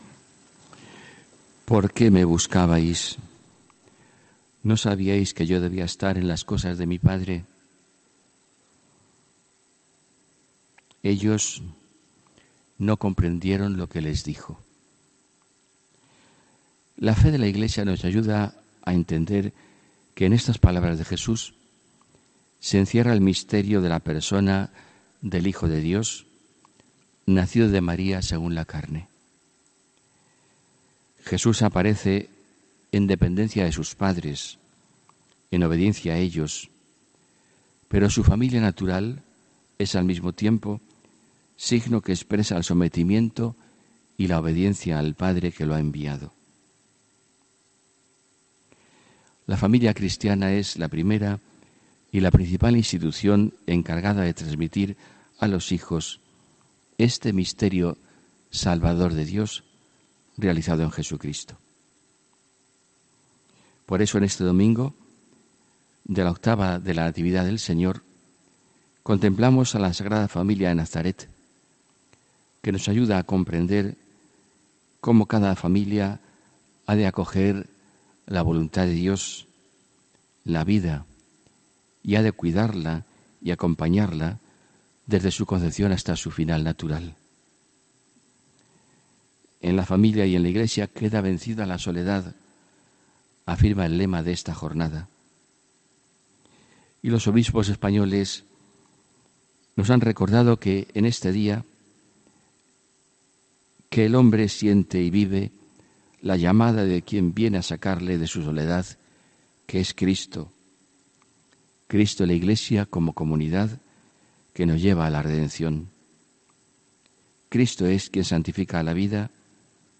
HOMILÍA 30 DICIEMBRE 2018